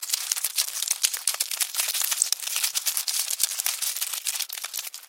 В коллекции есть разные варианты: от шороха лапок до стрекотания.
Шорох стремительных тараканов в погоне за едой